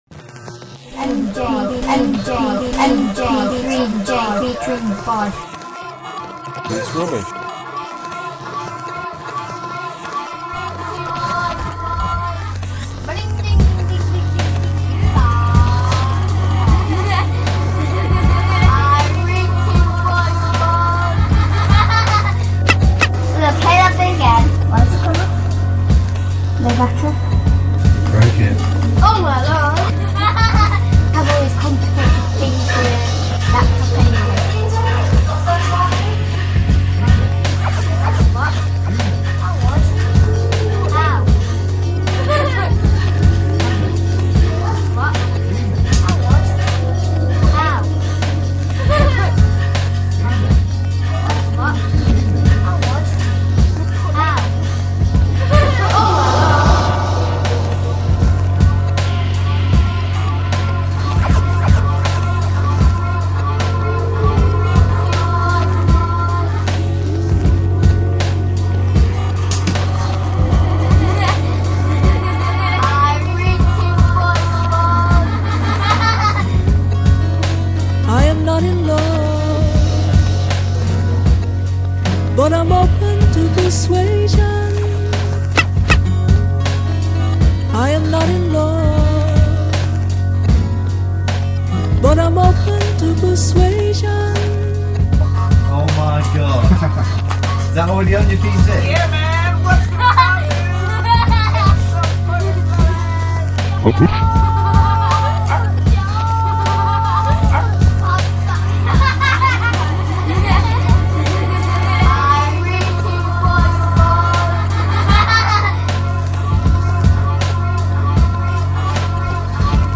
.....a mix using some beats